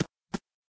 AV_footstep_runloop.ogg